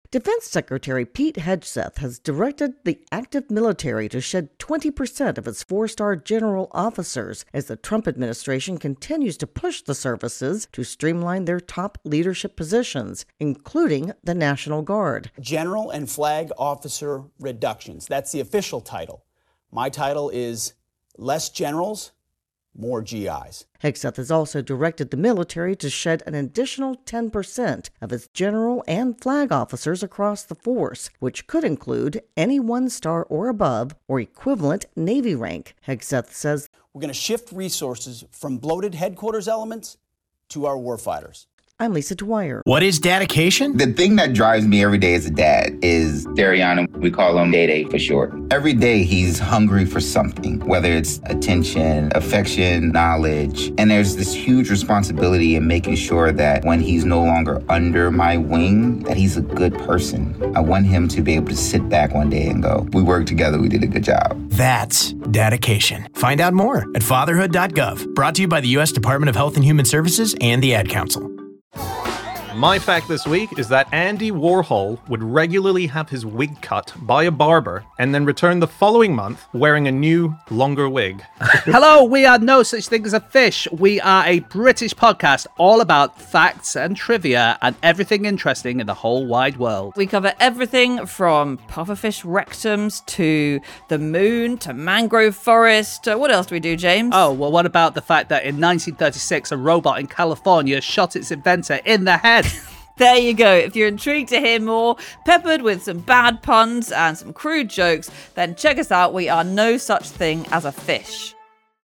reports on new cuts in military leadership.